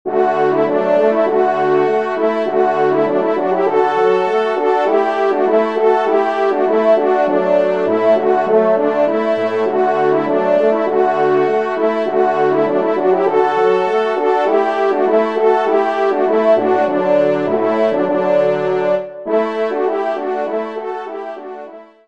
Genre : Divertissement pour Trompes ou Cors
ENSEMBLE                  Pupitre 1° Cor